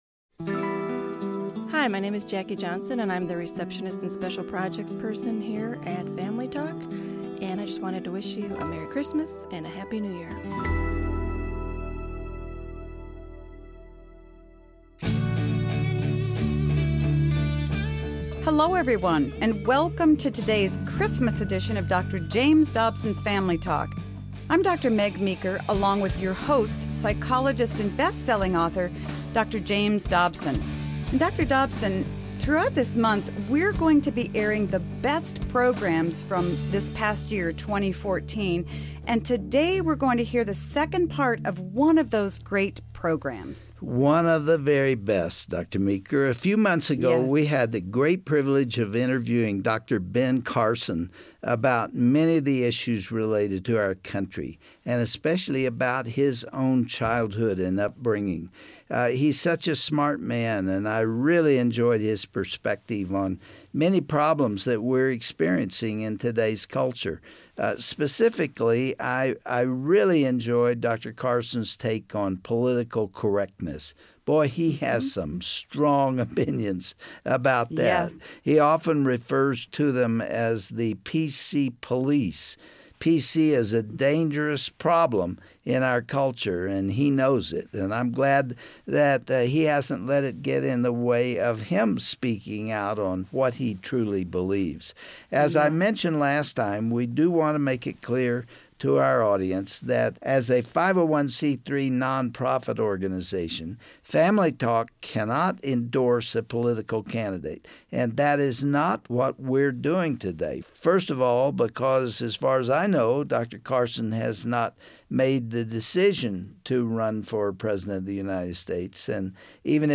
Dr. James Dobson interviews world renown pediatric neurosurgeon Dr. Ben Carson about his story and the future of America.